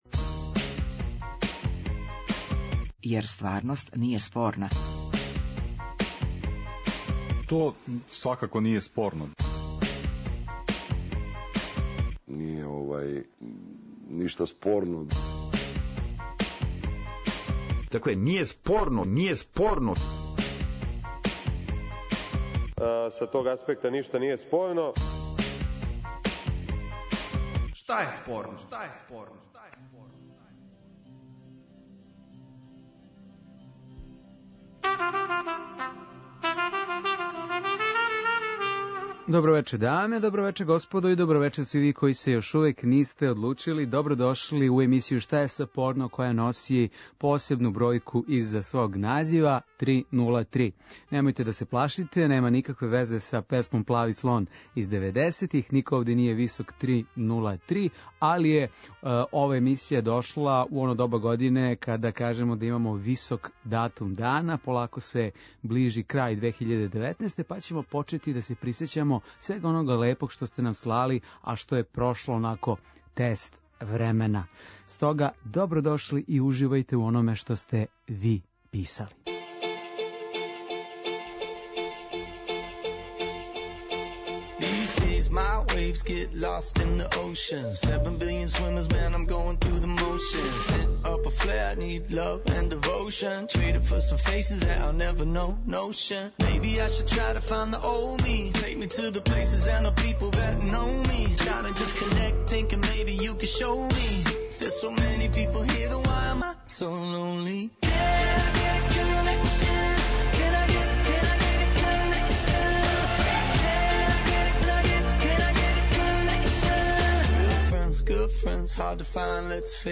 Радијски актуелно - забавни кабаре интерактивног карактера.